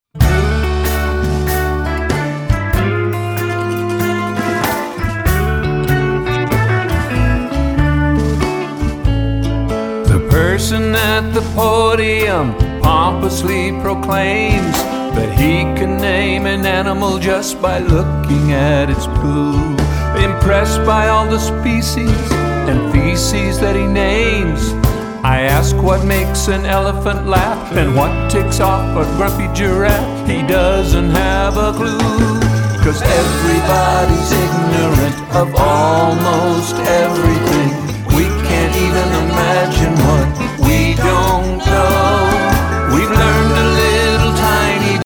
clever, cheeky, laugh-out-loud funny songs